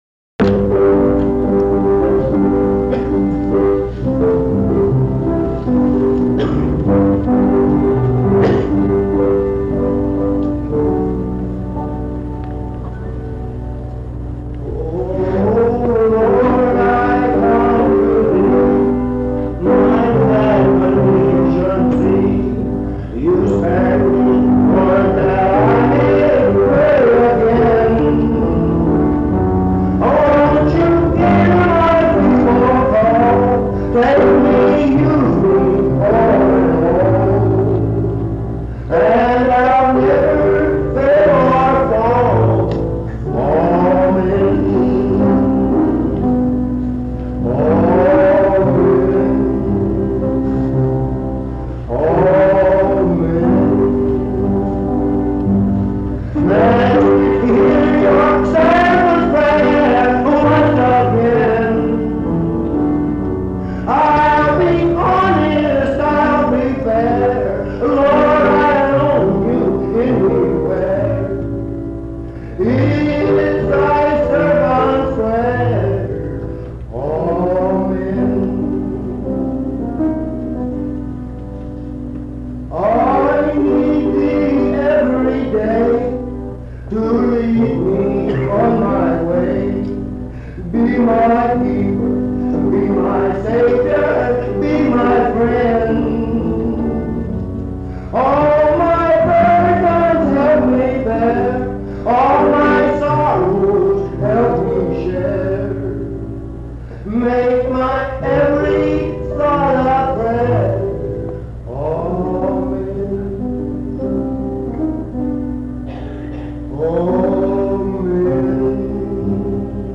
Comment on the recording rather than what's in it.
Highland Park Methodist Church, Morgantown, Monongalia County, WV.